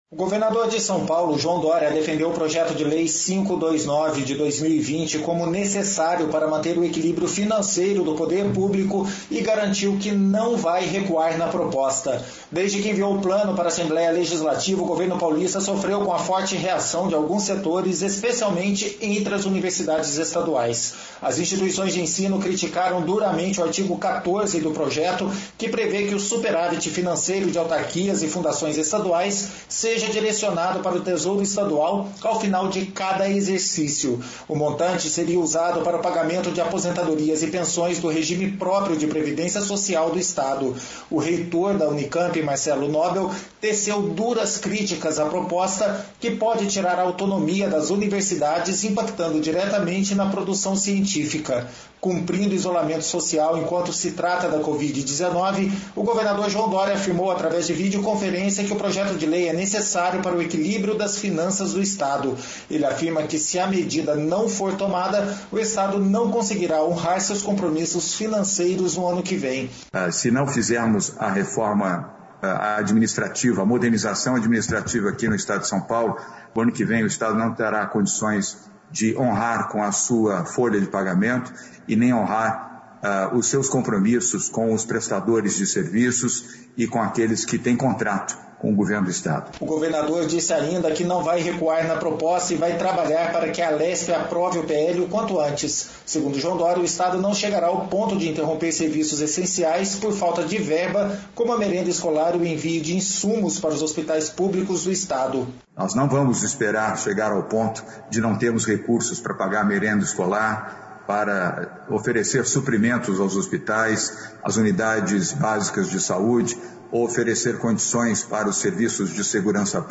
Cumprindo isolamento social enquanto se trata da covid-19, o governador João Doria afirmou através de videoconferência que o Projeto de Lei é necessário para o equilíbrio das finanças do estado.